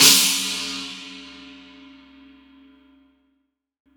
• Crash Cymbal Drum Sample C# Key 01.wav
Royality free crash cymbal drum sample tuned to the C# note. Loudest frequency: 5856Hz
crash-cymbal-drum-sample-c-sharp-key-01-IqZ.wav